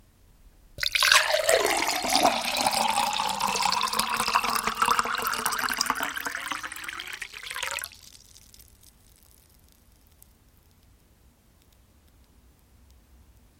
水倒入玻璃杯 " ZOOM0010
描述：水倒入一杯。可选择4个麦克风和3种不同的倾倒率，但标签已丢失！麦克风是：Beyer MCE86，Samson C03 on supercardioid，Behringer C2，AKG C1000 on hypercardioid。所有相同的位置（选择用于飞溅，玻璃环和气泡）; Behringer UB802调音台和Zoom H1录音机。
标签： 滴流 飞溅 玻璃 倒入 液体 浇注
声道立体声